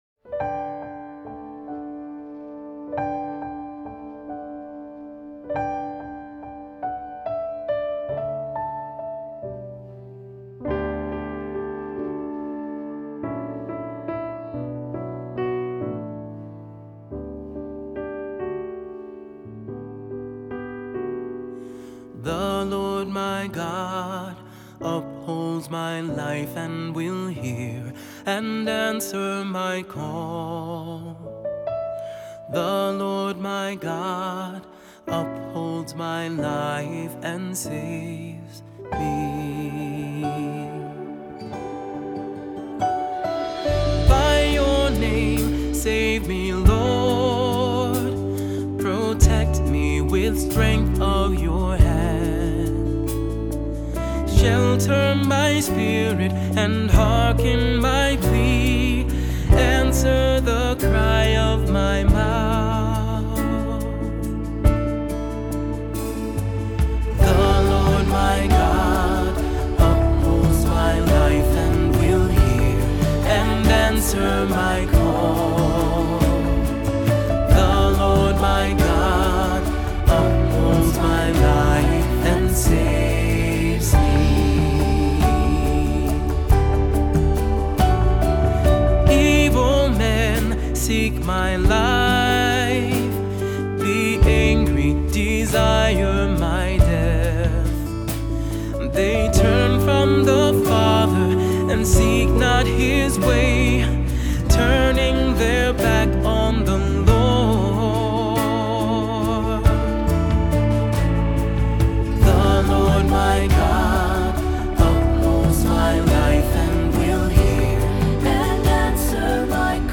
Voicing: SAB; Cantor; Assembly